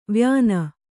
♪ vyāna